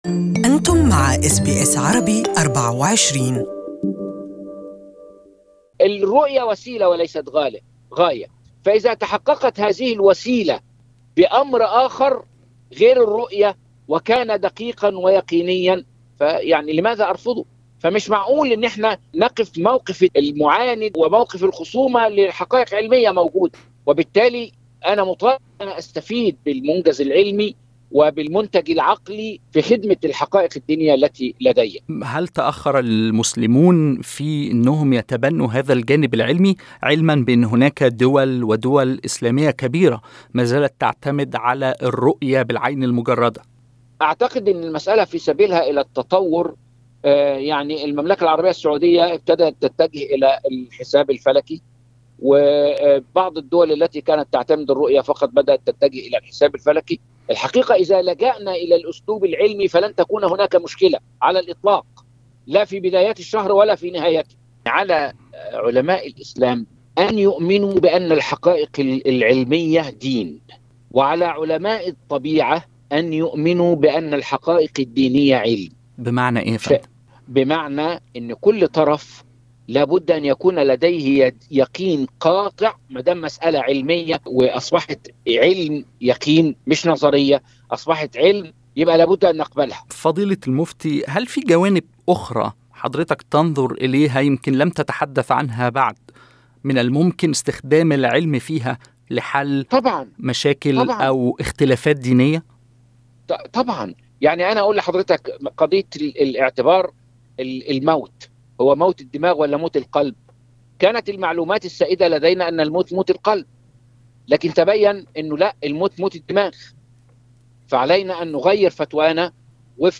المزيد من التفاصيل في التقرير الإذاعي المرفق.